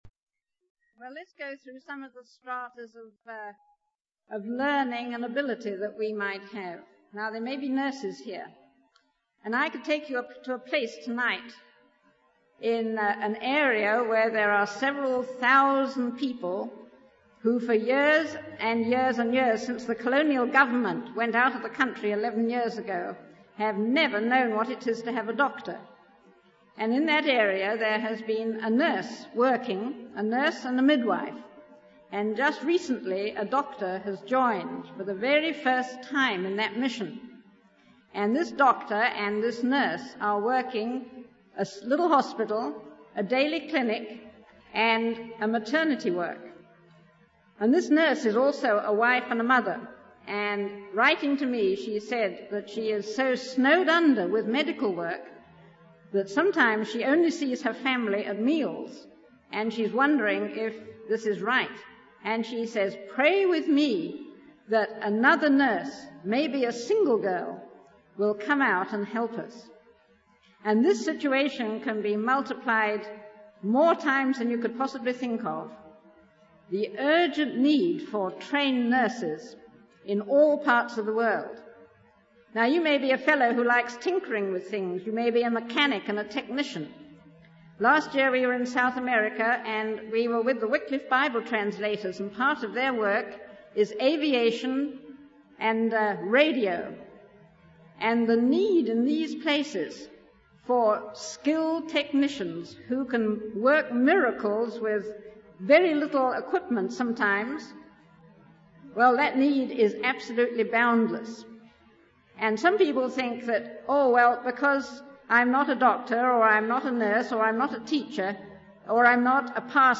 In this sermon, the speaker emphasizes the importance of each individual finding their niche in serving God.